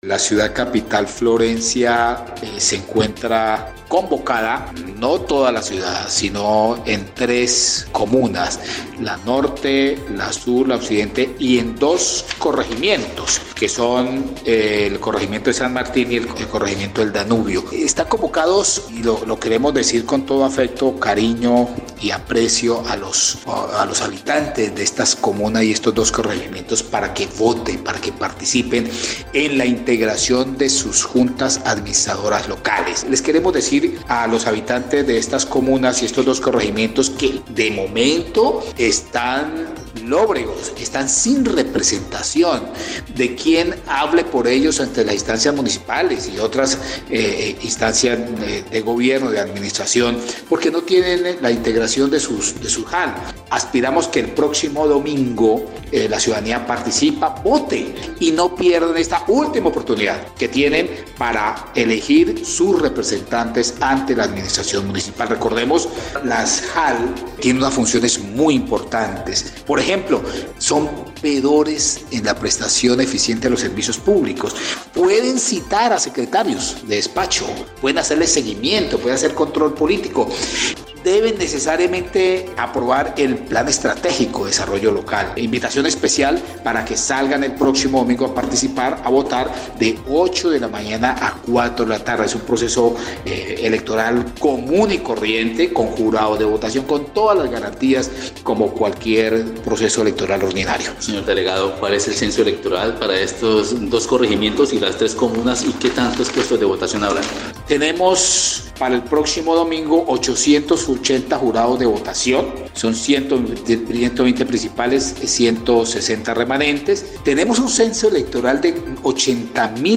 Así lo dio a conocer el Registrador Departamental de la Registraduría Nacional del Estado Civil, Humberto Carrillo, al tiempo indico que, 880 jurados de votación estarán pendientes en 22 puestos con 120 mesas de votación de 8am a 4pm.